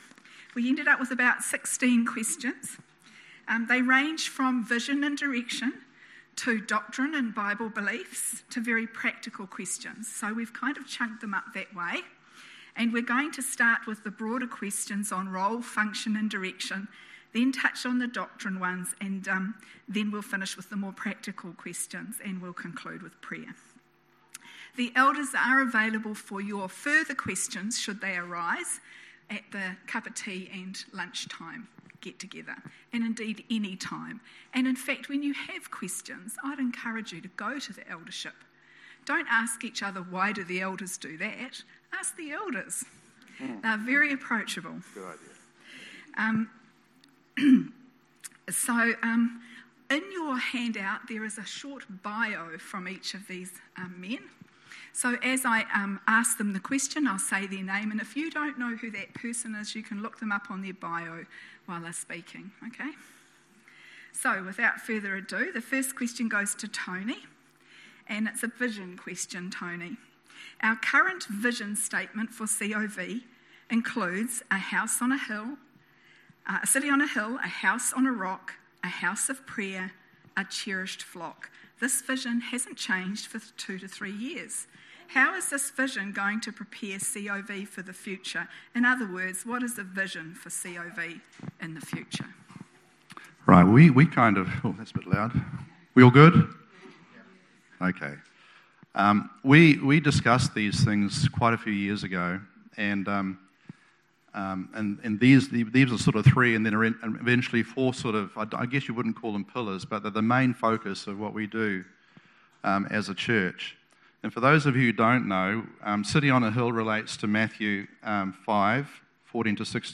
The Elders form a panel, to answer questions from Church Family.